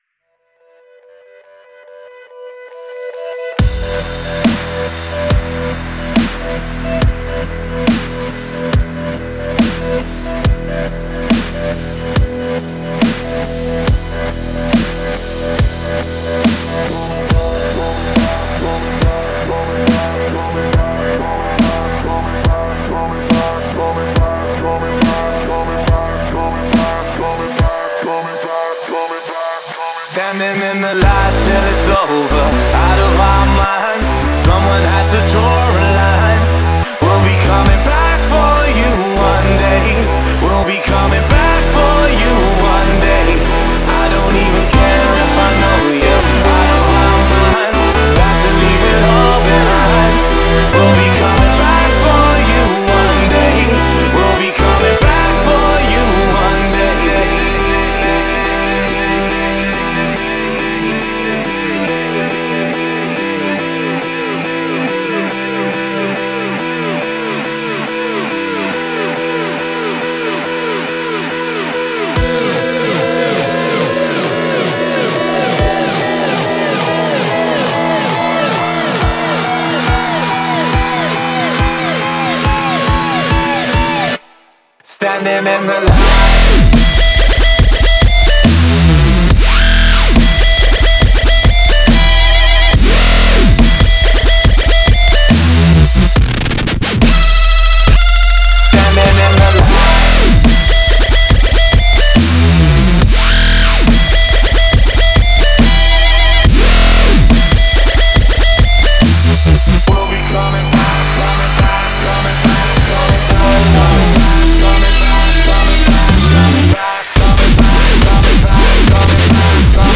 Раздел: Музыка » Dubstep